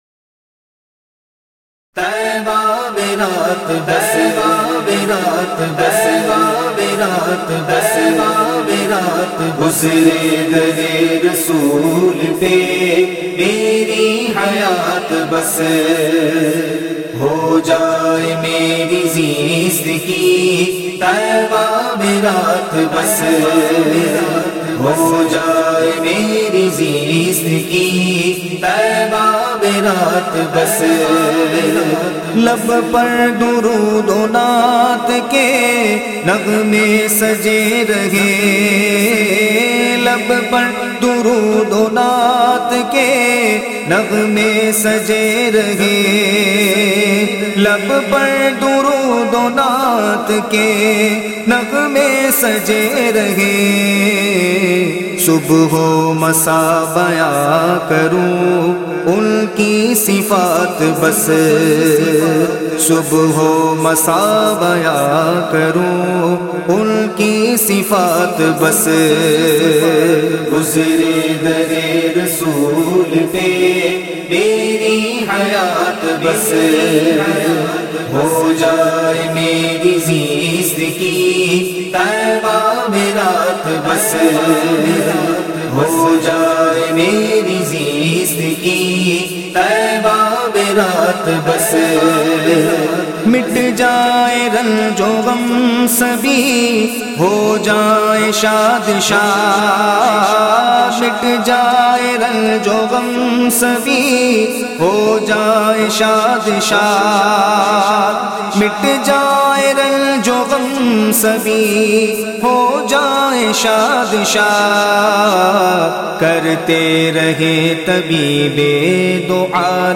نعت